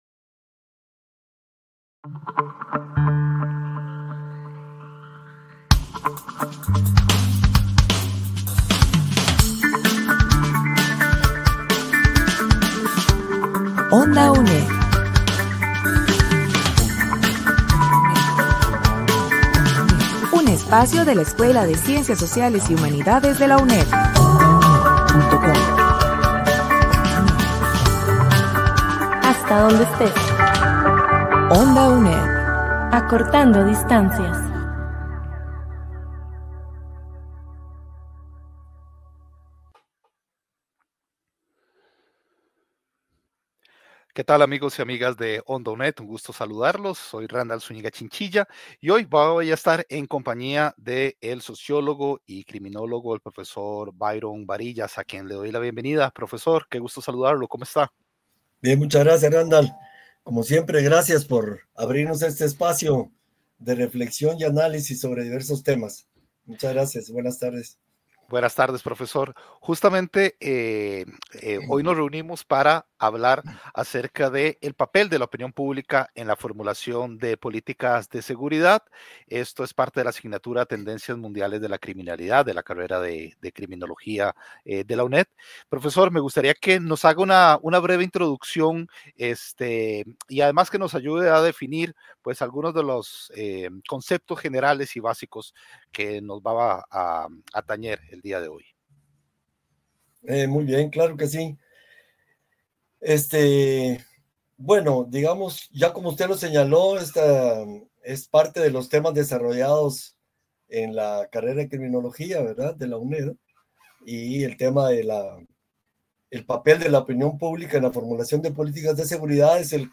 Programas de RADIO RADIO Papel de la opini?n p?blica en la forumulaci?n de pol?ticas de seguridad Su navegador no soporta la reproducci�n de audio HTML5.